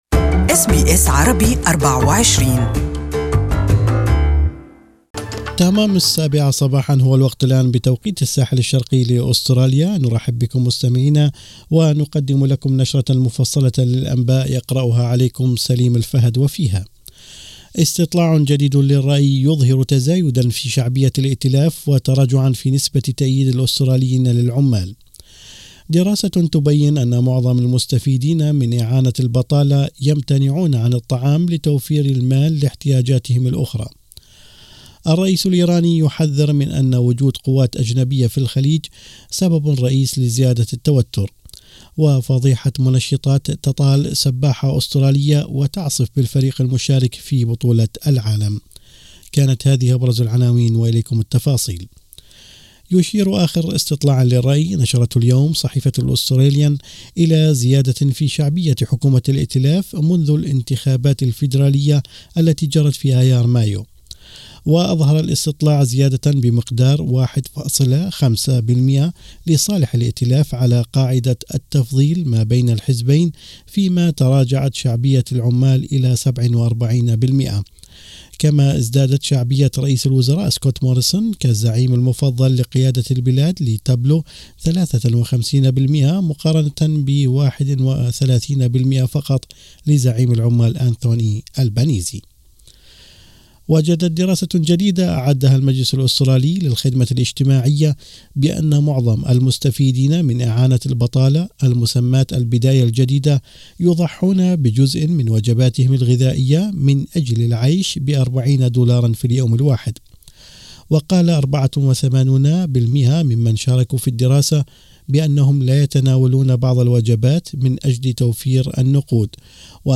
أخبار الصباح: أسعار الطاقة زادت بنسبة 158% عن 2015